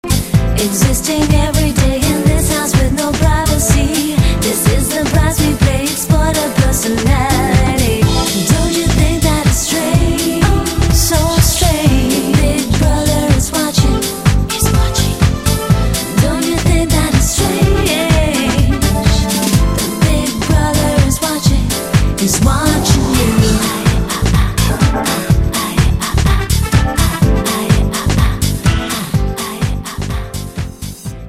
combine the hottest new dance grooves
a girl group trio with great harmonies.